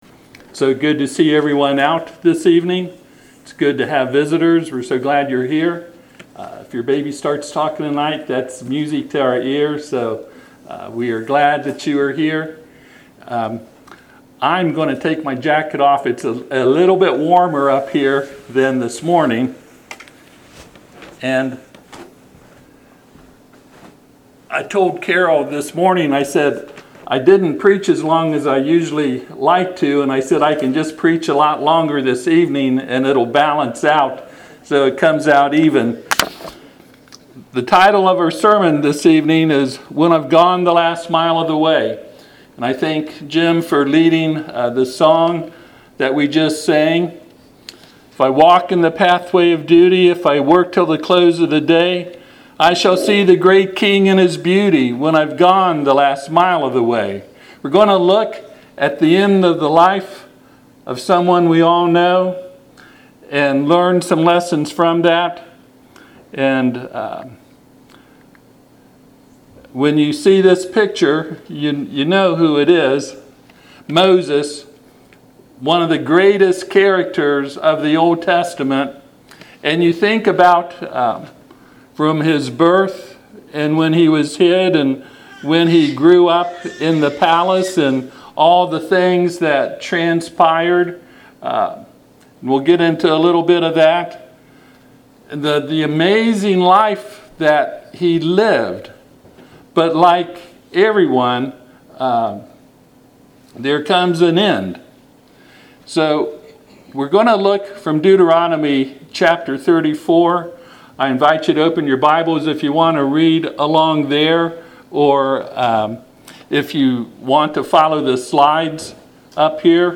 Passage: Deuteronomy 34:1-12 Service Type: Sunday PM